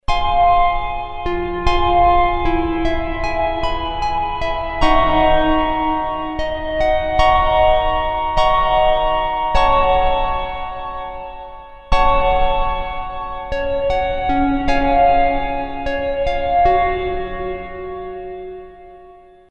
analog piano reverb
demo pad strings reverb